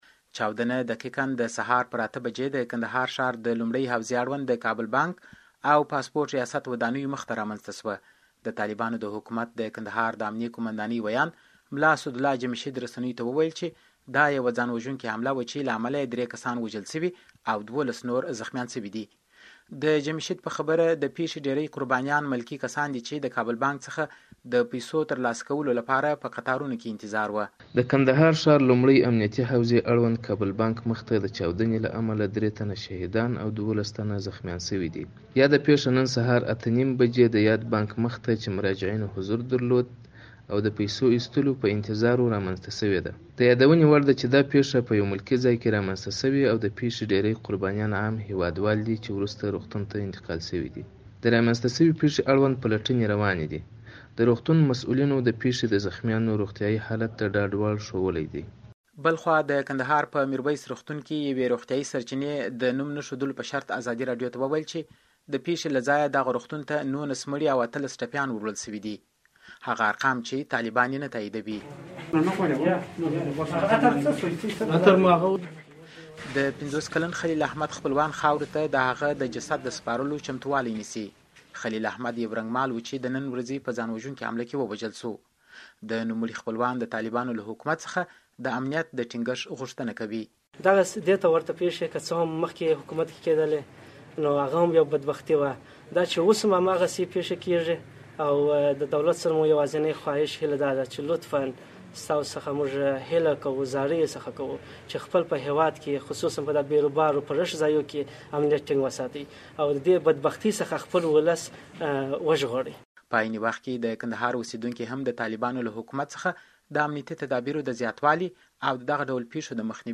د کندهار د چاودنې راپور